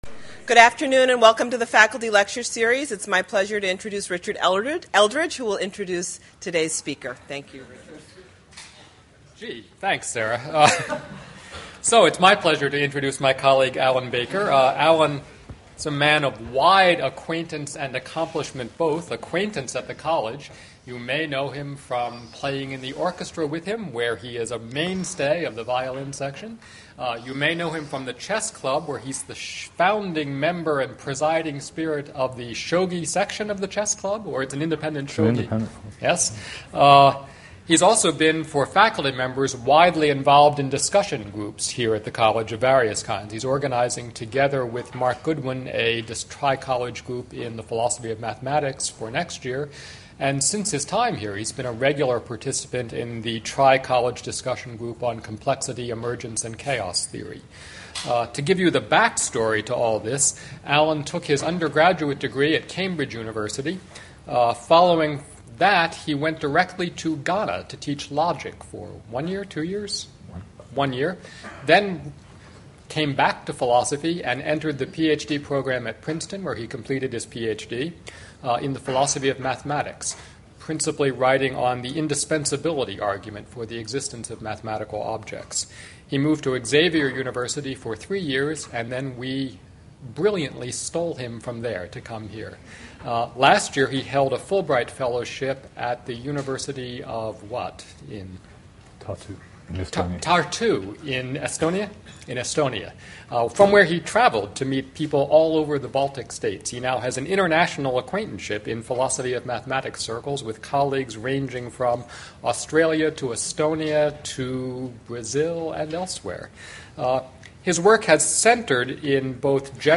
Faculty Lecture
faculty-lecture--2.mp3